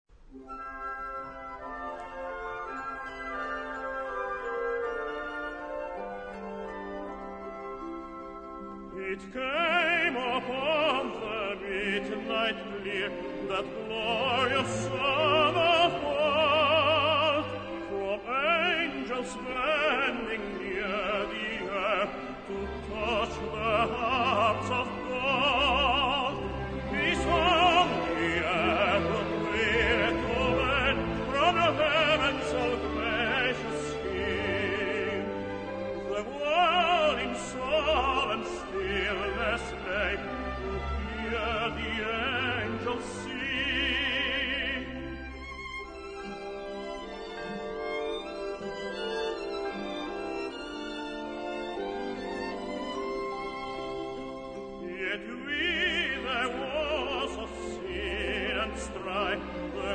key: C-major